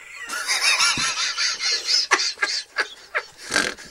描述：笑的人，谁做这个自然在Muttley风格（从卡通片Wacky Races知道的狗）。由AKG D80录音
Tag: 滑稽 muttley 古怪争